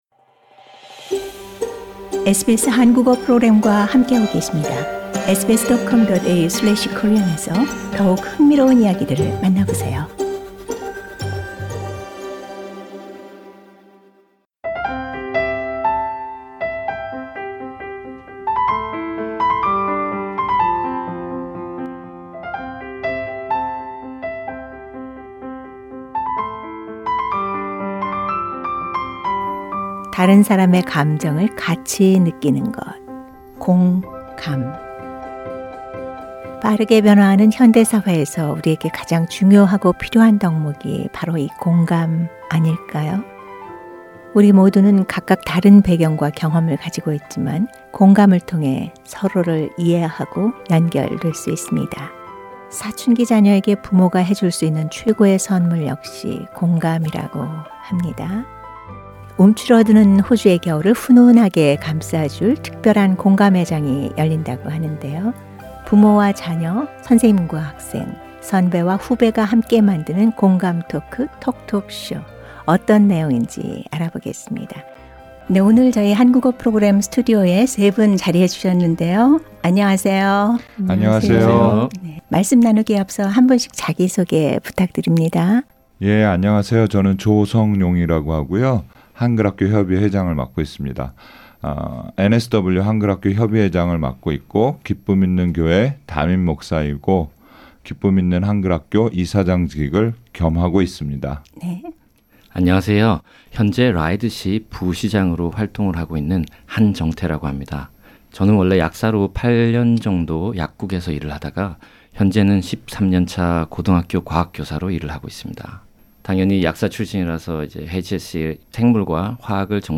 인터뷰: 우리 아이 미래의 직업군은?... 2024 공감 톡톡쇼에서 만나보세요!